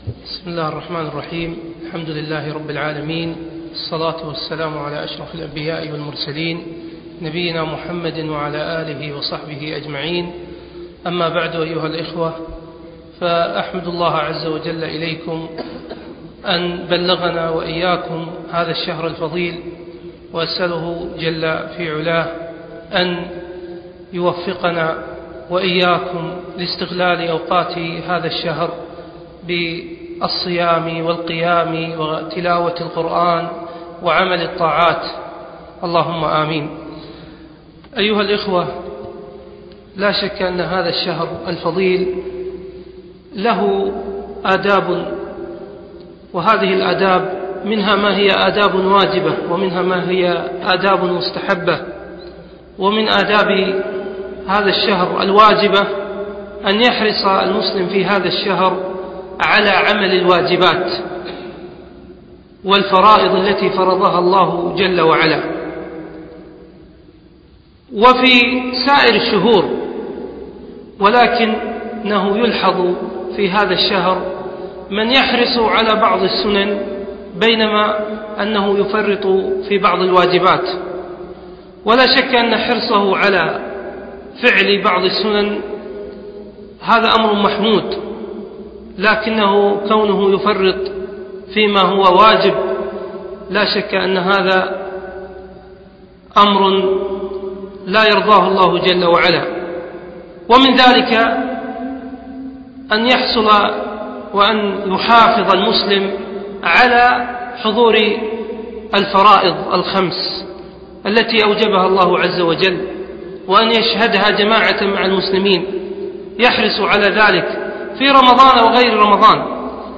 من لقاءات الشيخ في دولة الإمارات